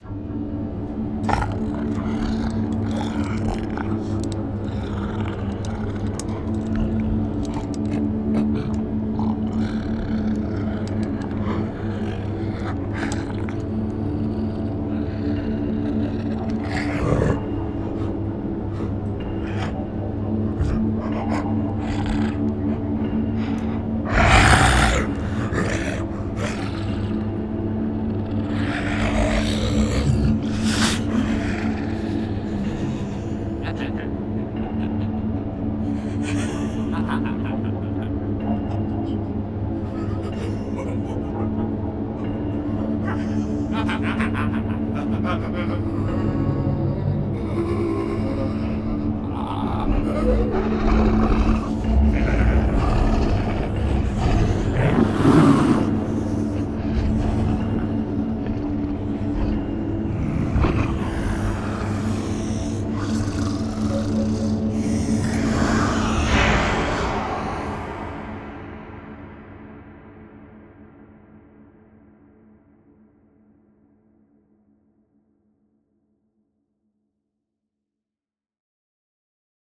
【电影/丧尸/恐怖音效采样】CEG Production Out The Door 1
在这个产品中表达了后世界末日的可怕声音，对急切饥饿的突变体的坚定渴望，准备扑向和撕毁它们的猎物，使他们真正相信接下来会发生什么。
使用这个由146个WAV样本组成的集合，通过配音进行游戏或视频制作，您可以轻松传达未来大门另一侧存在的令人恐惧的混乱和世界不可避免的整个气氛。
–通道：2个通道（立体声）